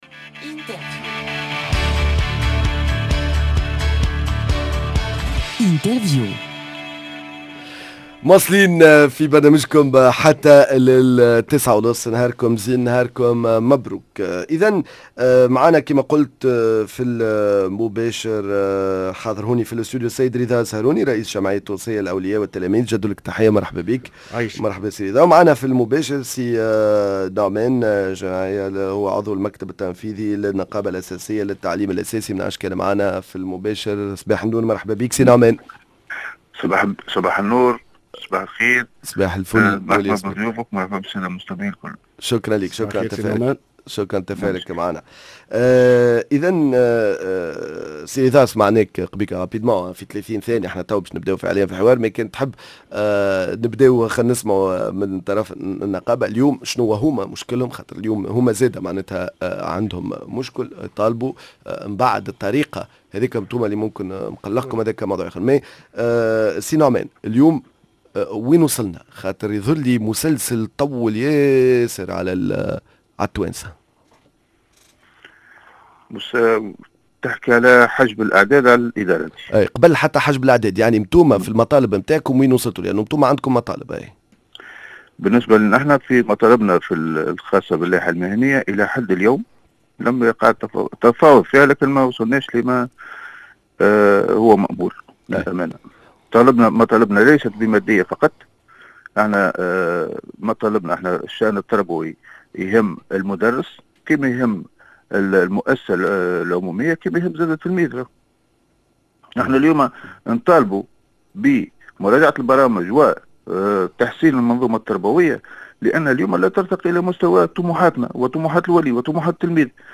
نقاش فالمباشر بين النقابة العامة للتعليم الأساسي و الجمعية التونسية للأولياء و التلاميذ حول تواصل التمسك بحجب الأعداد و الأولياء يطالبون بحل الإشكال